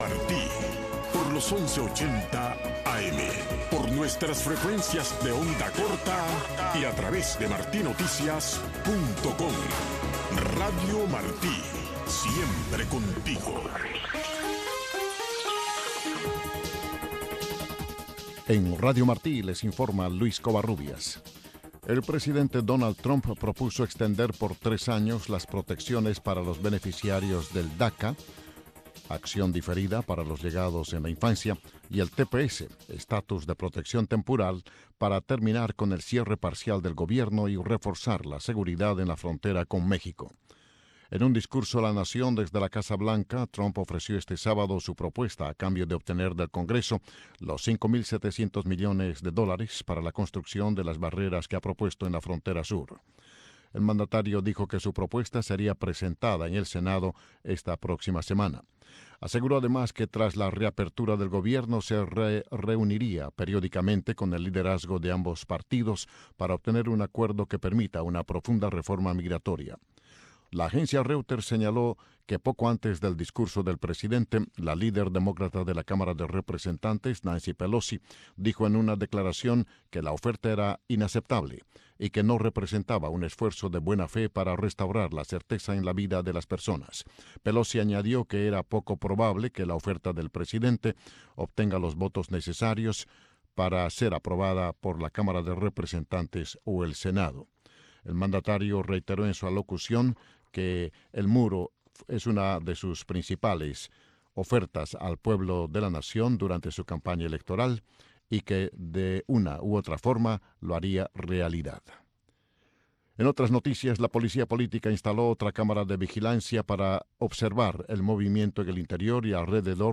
Radio Marti presenta Tribuna Libre. Los acontecimientos que omitieron tus libros de historia, contados por uno de sus protagonistas. Un programa conducido por el Doctor: Luis Conté Agüero.